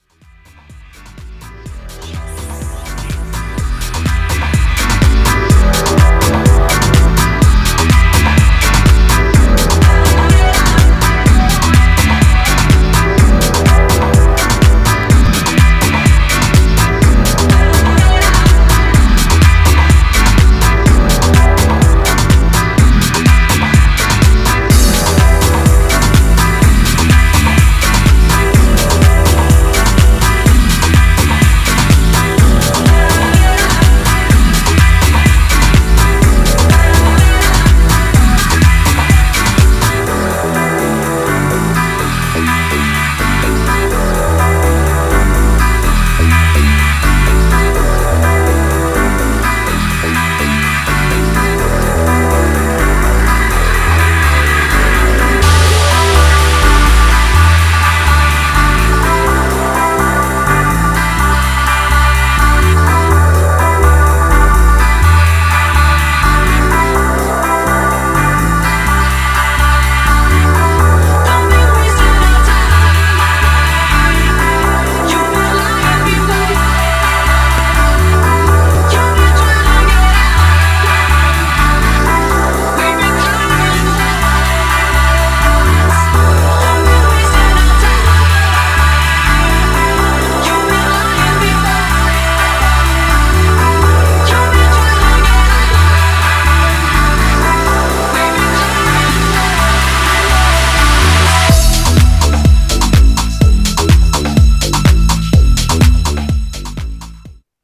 Styl: Progressive, House, Breaks/Breakbeat